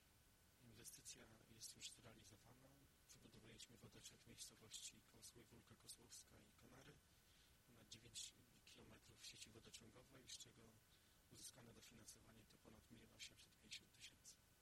Wypowiedź burmistrza gminy Tłuszcz, Pawła Marcina Bednarczyka